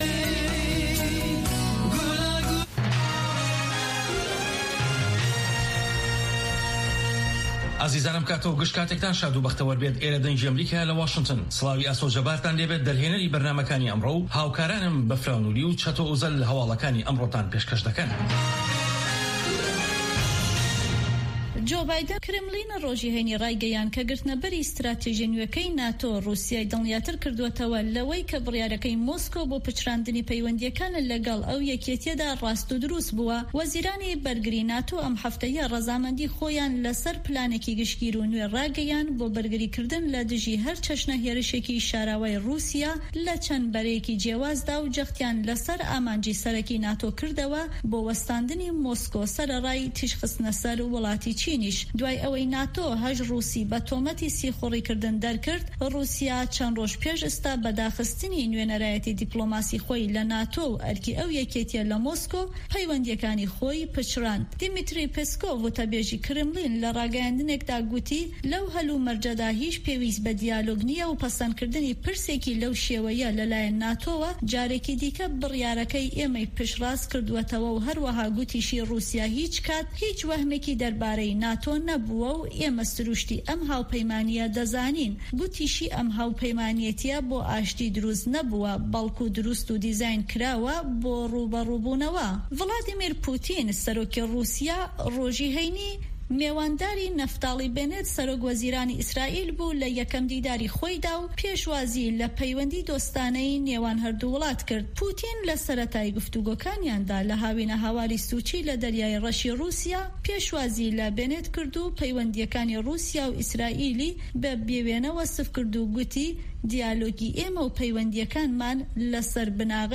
Nûçeyên 1’ê paşnîvro
Nûçeyên Cîhanê ji Dengê Amerîka